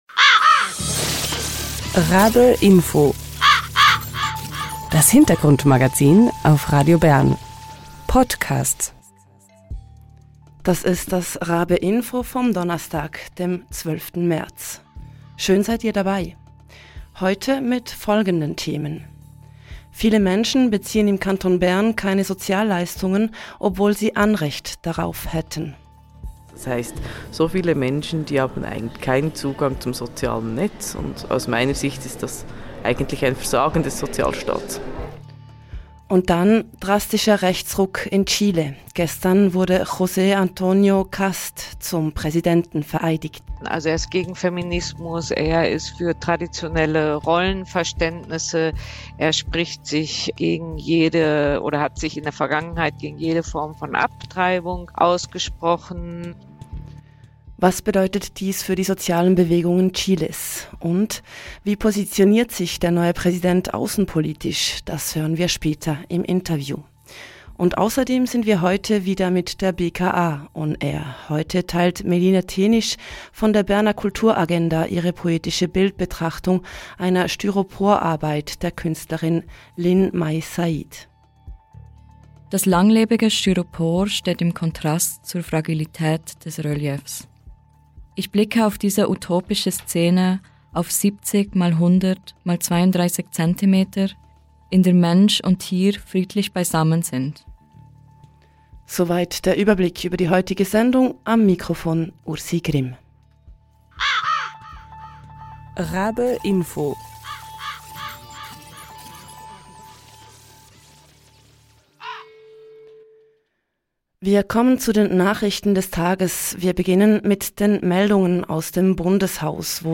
Das hören wir im Interview.